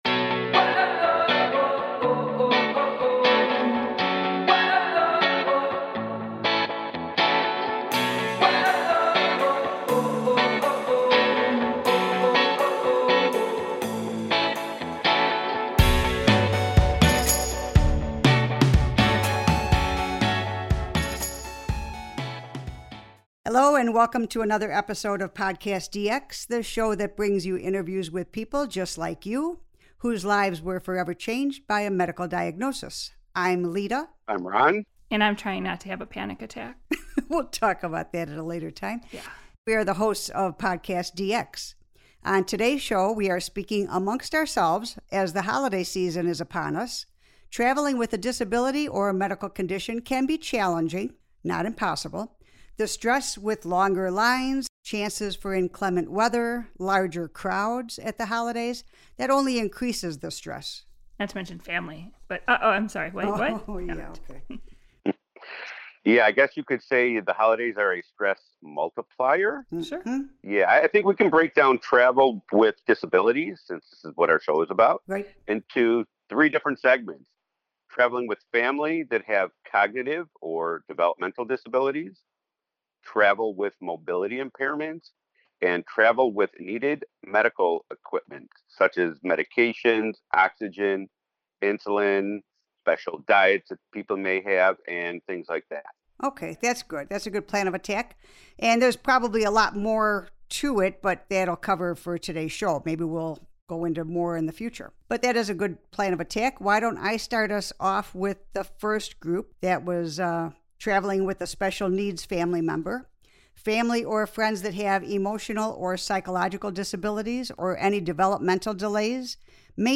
This episode is a forum with the PodcastDX co-hosts